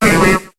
Cri de Chétiflor dans Pokémon HOME.